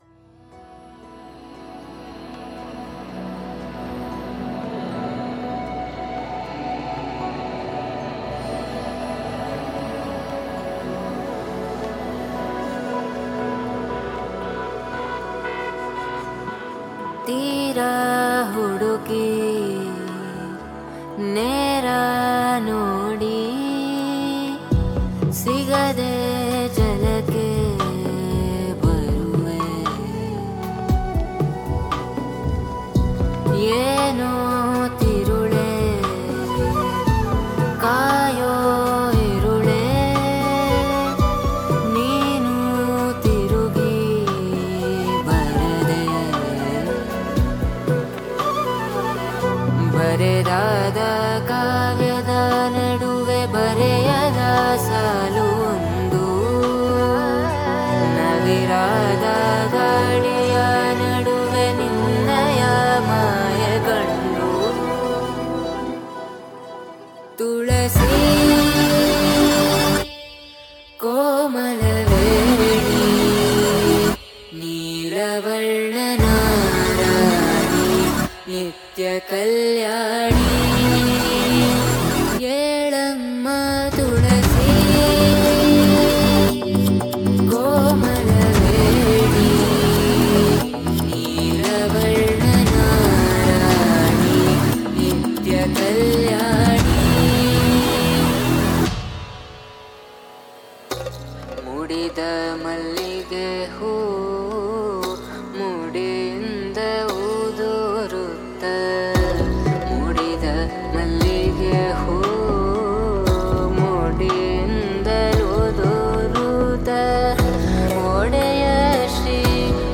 Melody, Love, Feel Good, bgm, soulful, peaceful, Emotional